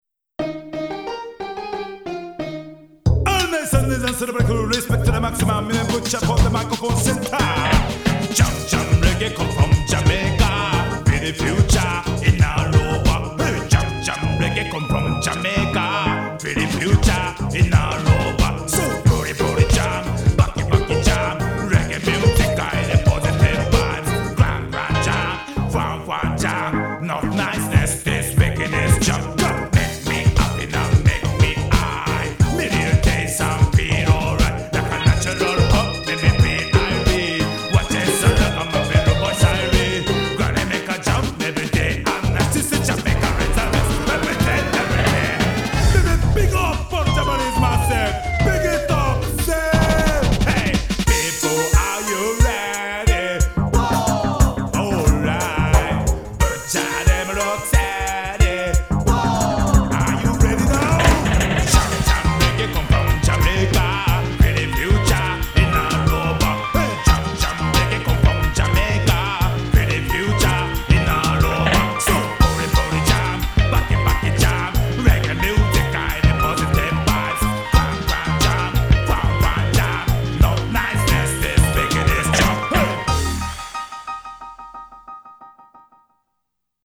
BPM90
Audio QualityPerfect (High Quality)
Comments[REGGAE]